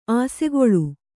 ♪ āsegoḷu